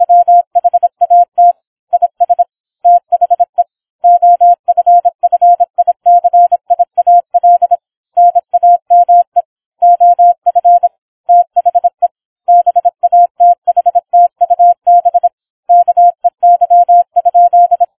Morse challenge